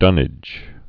(dŭnĭj)